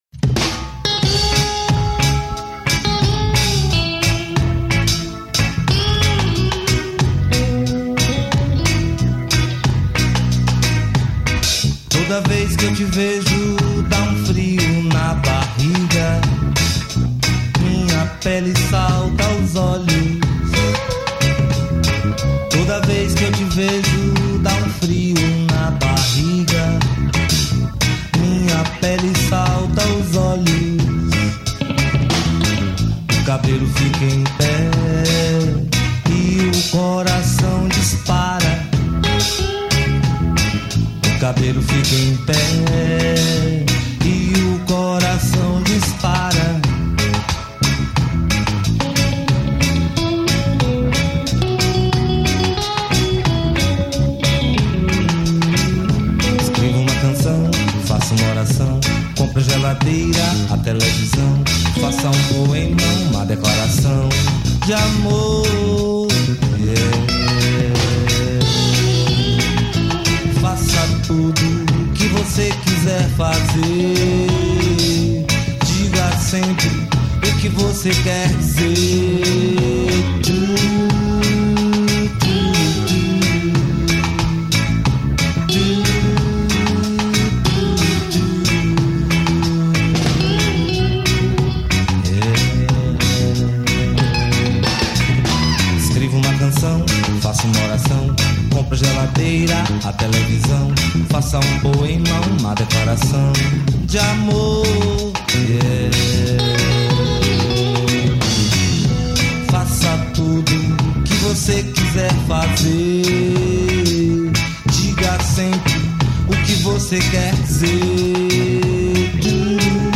2154   03:03:00   Faixa:     Reggae